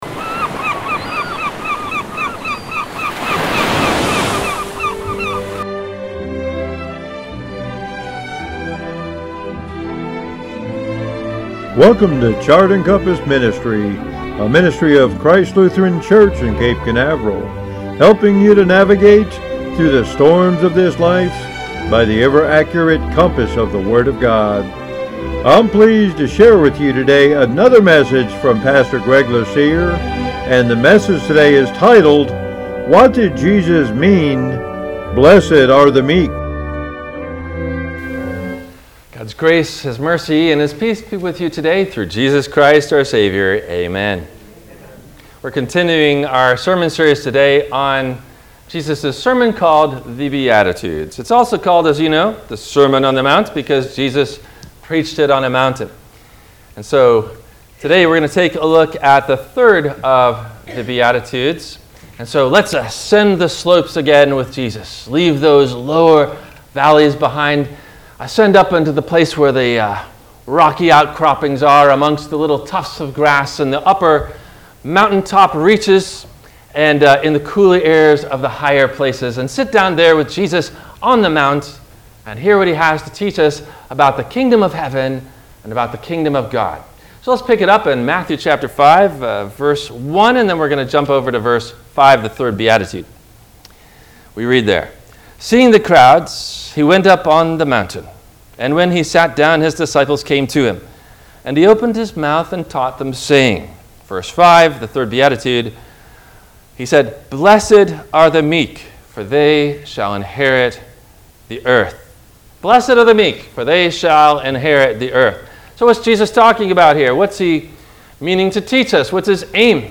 What Did Jesus Mean … Blessed Are The Meek? – WMIE Radio Sermon – August 28 2023
No Questions asked before the Message.